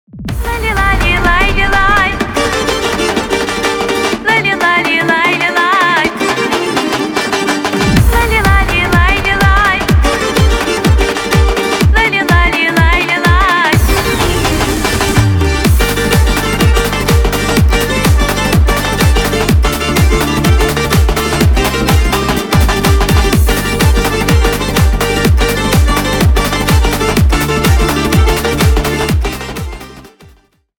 Танцевальные # без слов
кавказские